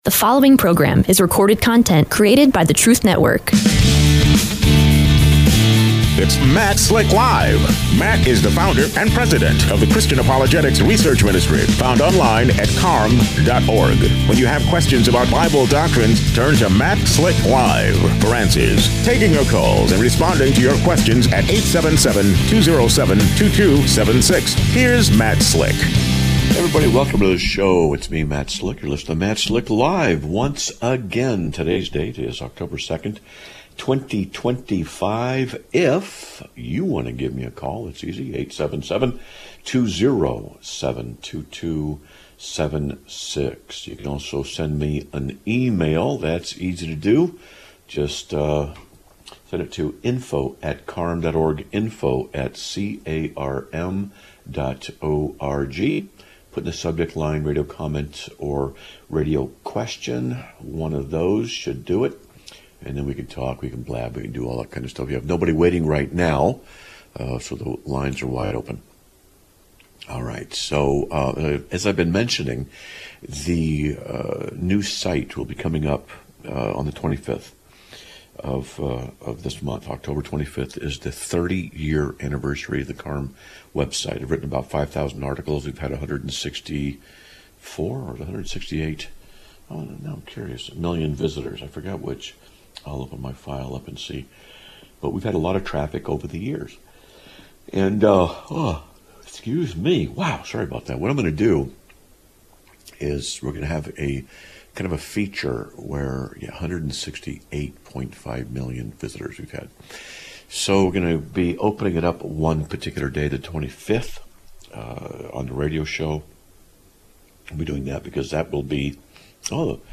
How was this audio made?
Live Broadcast of 10/02/2025